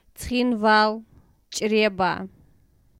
Tskhinvali (Georgian: ცხინვალი [ˈt͡sʰχinʷali] ) or Tskhinval (Ossetian: Цхинвал, Чъреба, romanized: Cxinval, Čreba, [t͡sχinˈvɒɫ, ˈt͡ʃʼɾʲebɑ]
Tskhinval.ogg.mp3